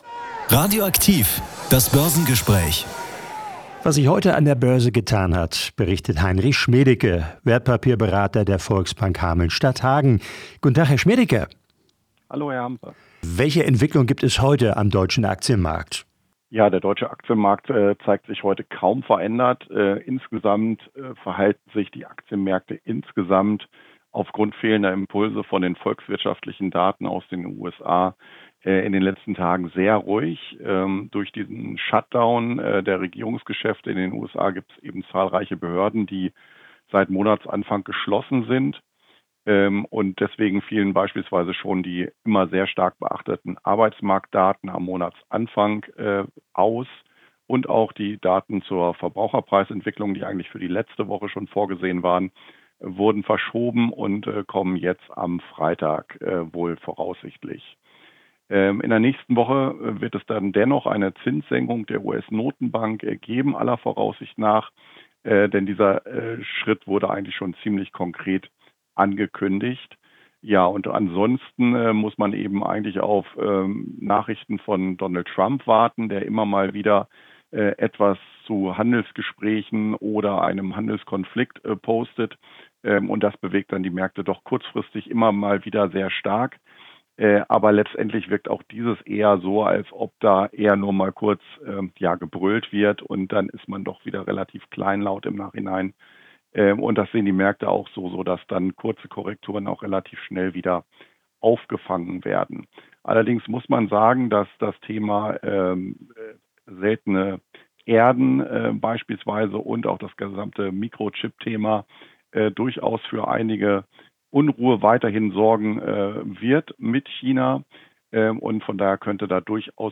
Börsengespräch am 22.Oktober